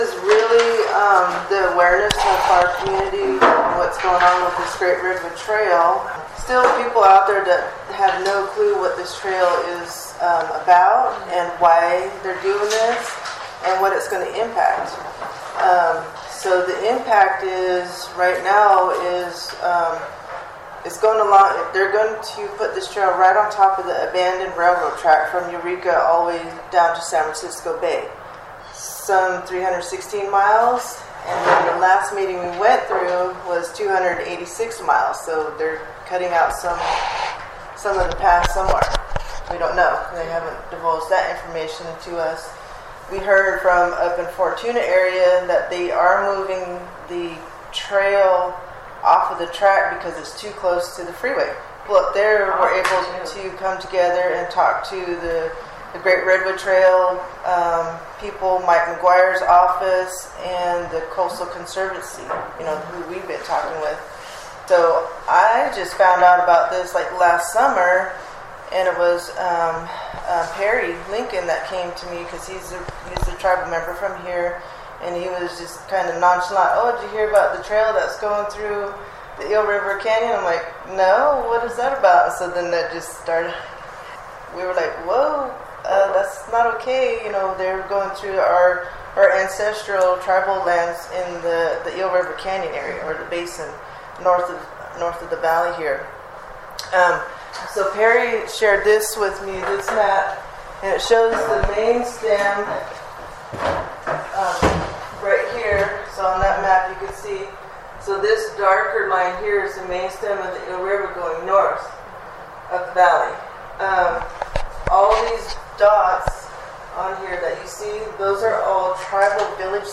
A recent community meeting in Round Valley explored the concerns and strong opposition to the Great Redwood Trail that is shared by Round Valley Tribal Members as well as non tribal community members.